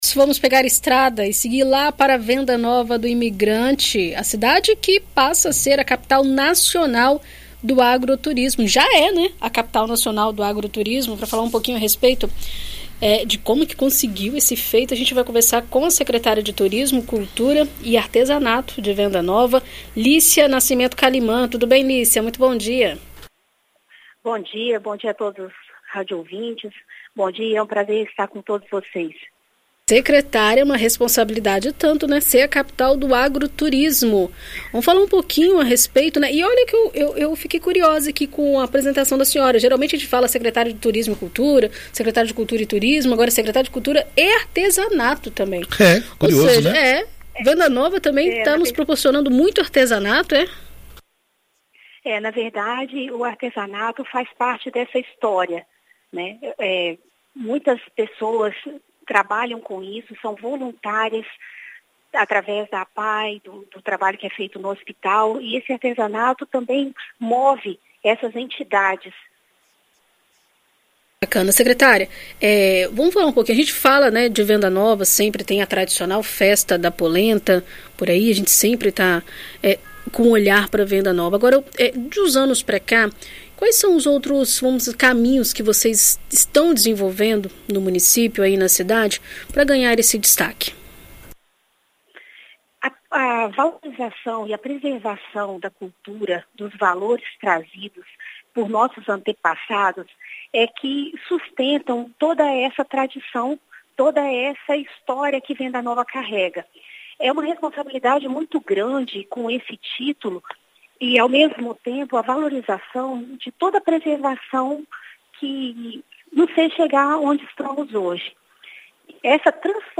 Em entrevista à BandNews FM Espírito Santo, a secretária de Turismo, Cultura e Artesanato de Venda Nova, Lícia Nascimento Caliman, fala sobre os atrativos do município responsáveis pelo título.